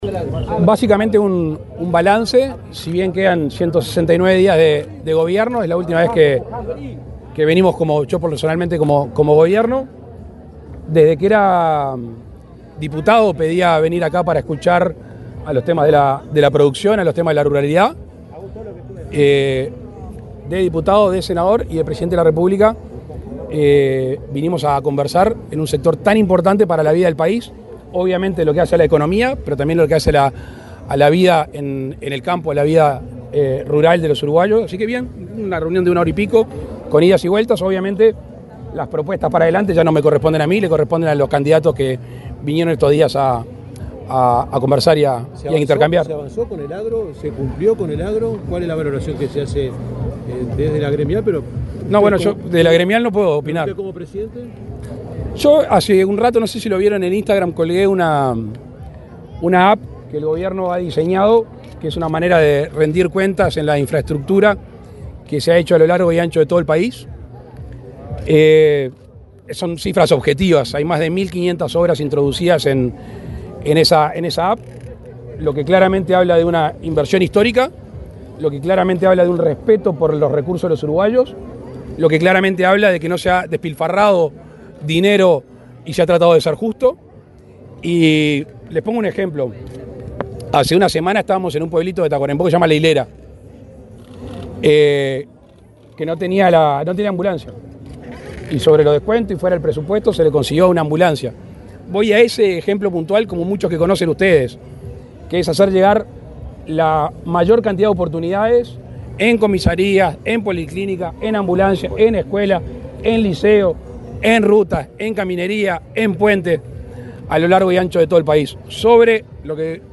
Declaraciones del presidente Lacalle Pou a la prensa
El presidente de la República, Luis Lacalle Pou, se reunió, este viernes 13, con la directiva de la Asociación Rural del Uruguay en la Expo Prado 2024